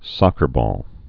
(sŏkər-bôl)